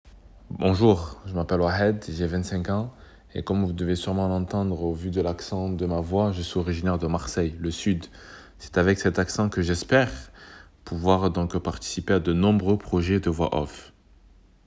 Mon accent
Voix - Ténor